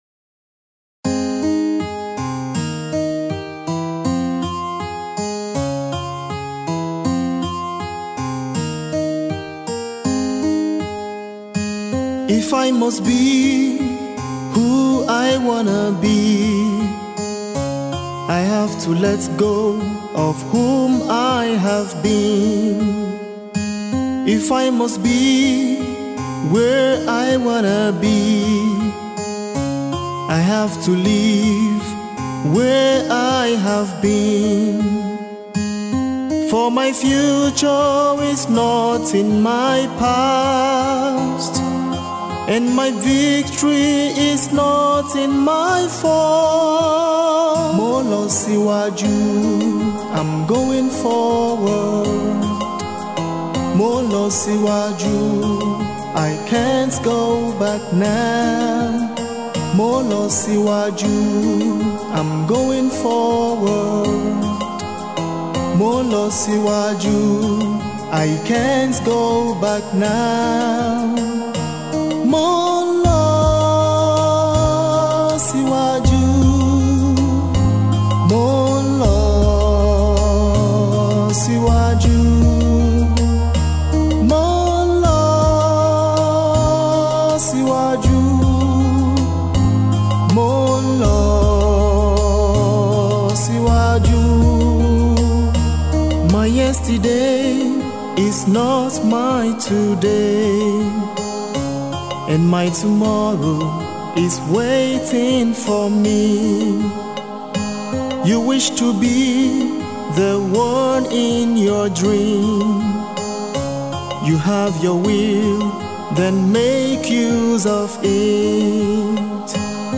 an R&B motivational music.